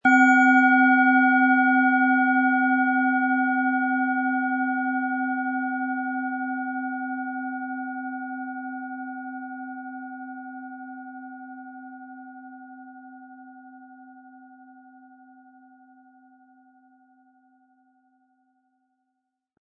Planetenschale® Fühle Dich wohl & DNA-Reparatur mit DNA-Ton, Ø 10,5 cm, 100-180 Gramm inkl. Klöppel
Planetenton 1
Im Lieferumfang enthalten ist ein Schlegel, der die Schale wohlklingend und harmonisch zum Klingen und Schwingen bringt.